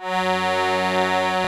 F#2 ACCORD-R.wav